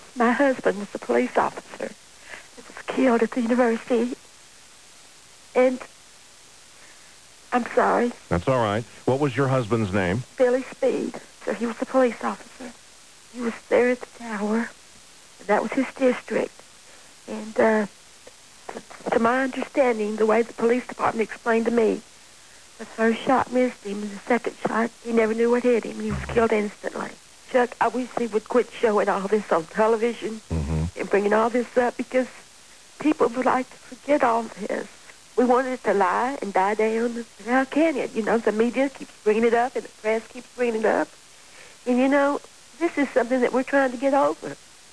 Audio clip courtesy of KLBJ-AM radio, August 1, 1996.